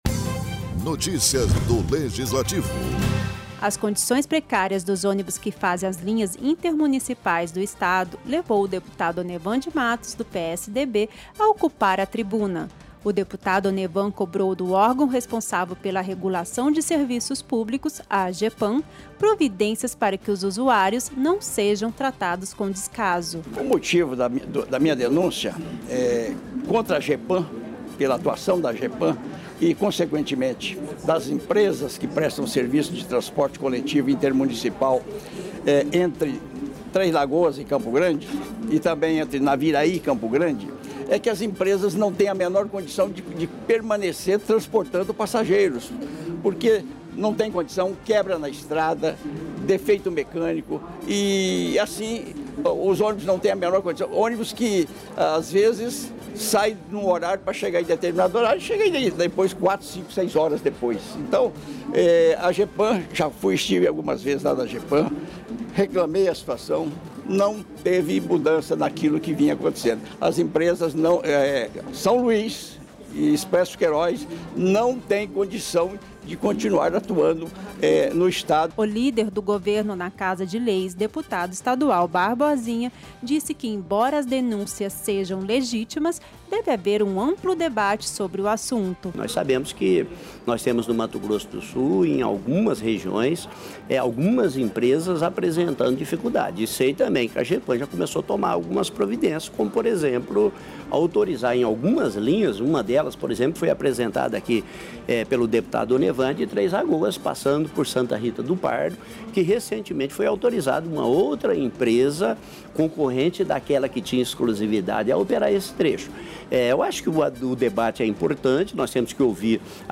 O deputado estadual Onevan de Matos ocupou a tribuna da Assembleia Legislativa, na sessão plenária desta terça-feira (26), para denunciar a precariedade do serviço de transporte intermunicipal prestado no Estado.